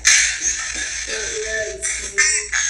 Recording 4 (E.V.P.)
graceland-too-evp-dont-let-them-see-me.m4a